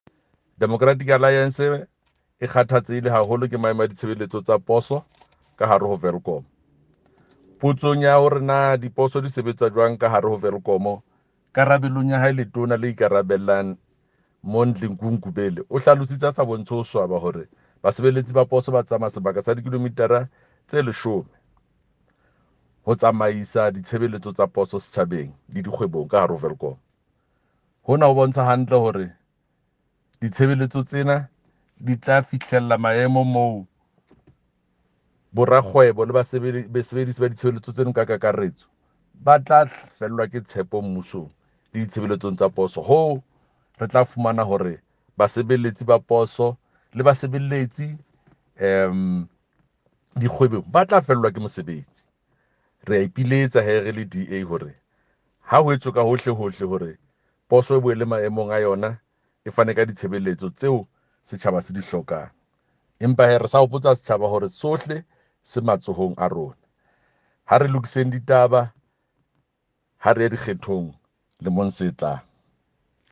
Sesotho by Jafta Mokoena MPL.
Sotho-voice-Jafta-5.mp3